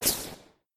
whiz_sub_02.ogg